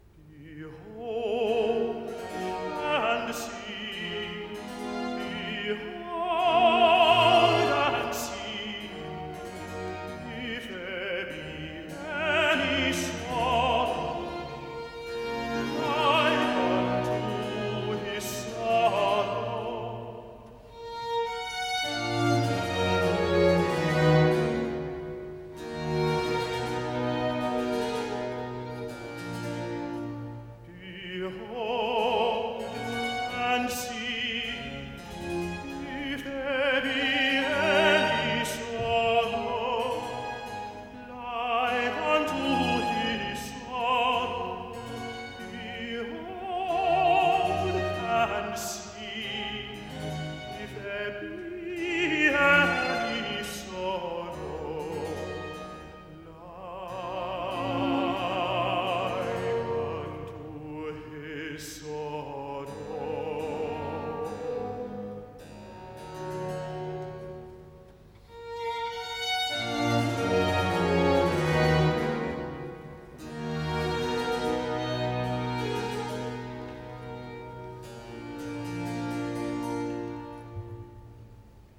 Aria-tenor